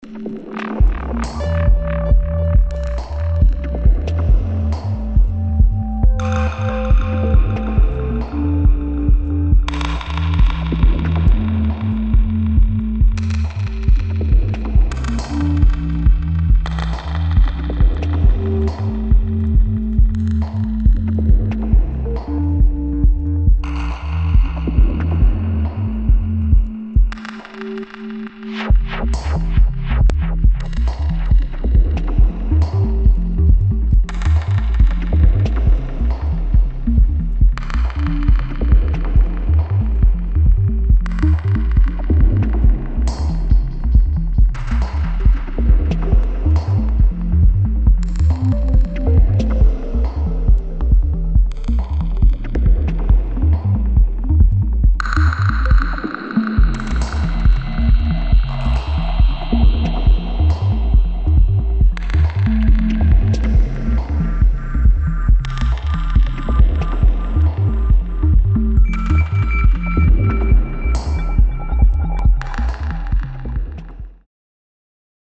[ BASS / ELECTRONIC / TECHNO ]